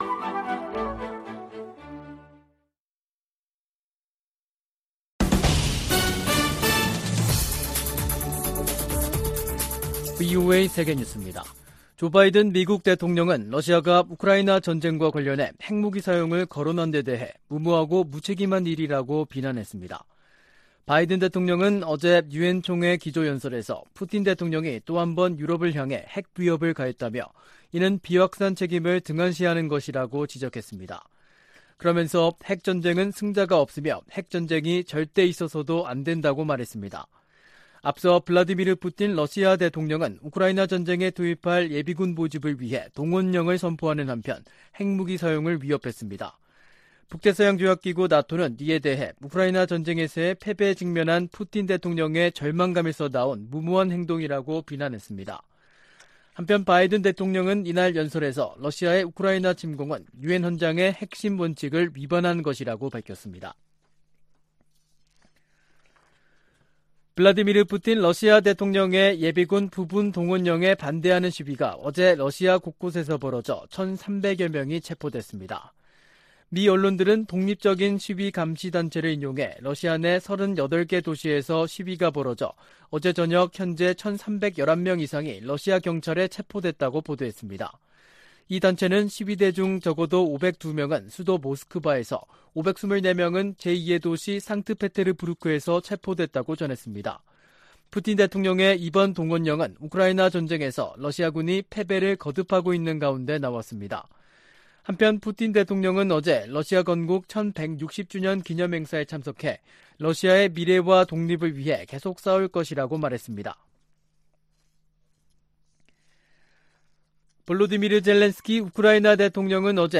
VOA 한국어 간판 뉴스 프로그램 '뉴스 투데이', 2022년 9월 22일 3부 방송입니다. 조 바이든 대통령과 윤석열 한국 대통령이 유엔총회가 열리고 있는 뉴욕에서 만나 북한 정권의 위협 대응에 협력을 재확인했습니다. 한일 정상도 뉴욕에서 대북 협력을 약속하고, 고위급 외교 채널을 통해 양국 관계 개선 노력을 가속화하기로 합의했습니다. 바이든 대통령은 유엔총회 연설에서 북한의 지속적인 유엔 제재 위반 문제를 지적했습니다.